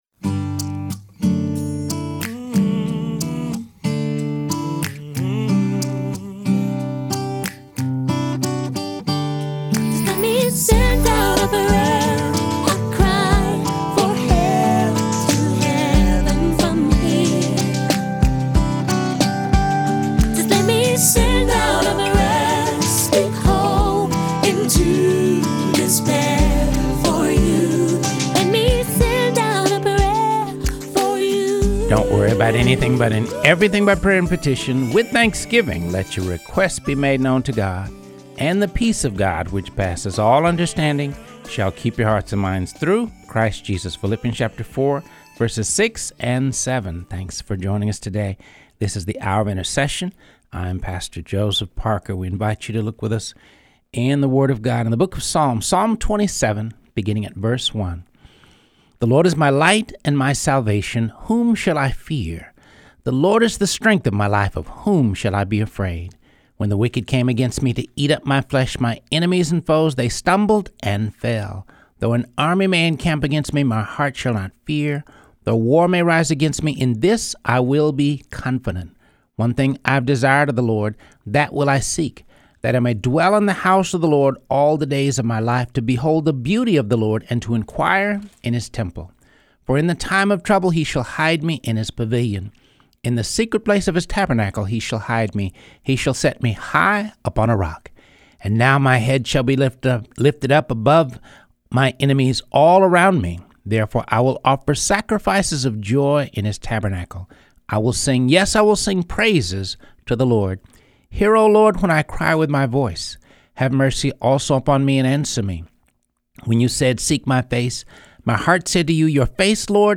Teaching: Teach My Hands to War